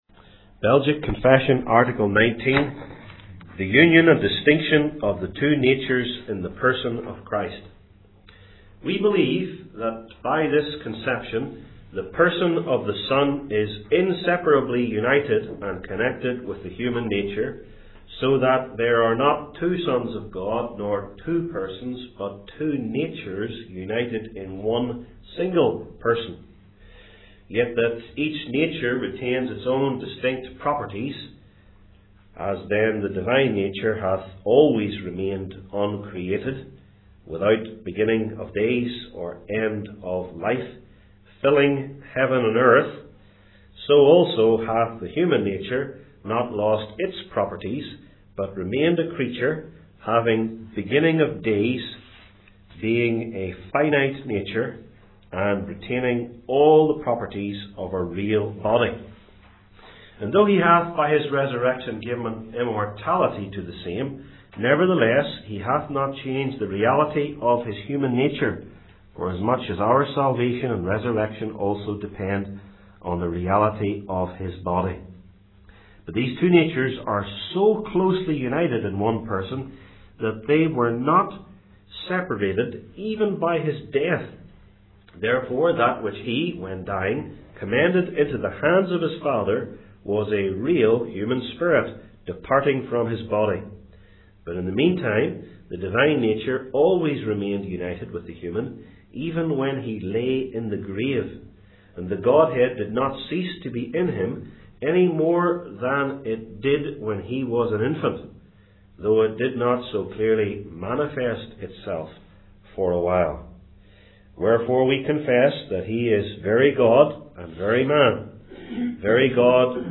Service Type: Belgic Confession Classes